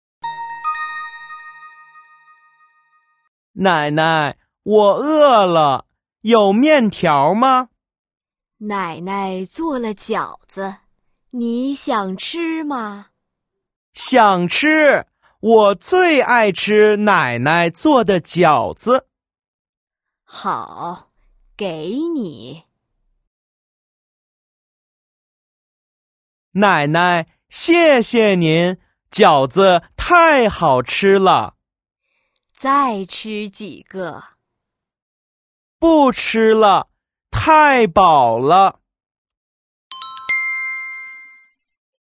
Hội thoại 1:
Hội thoại 2：